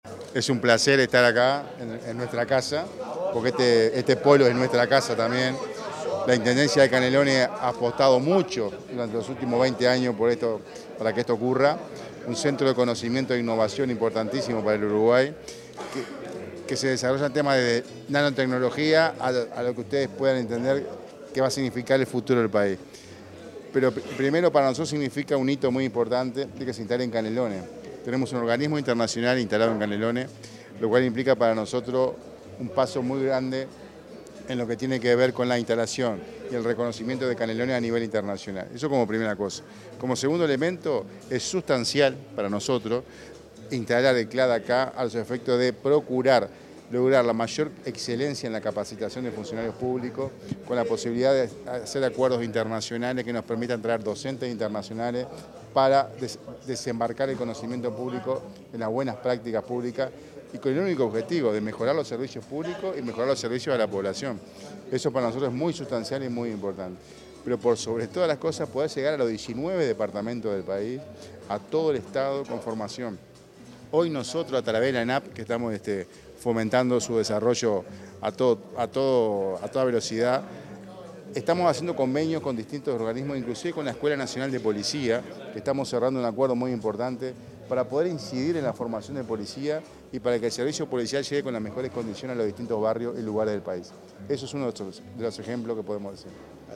Declaraciones del director de la ONSC, Sergio Pérez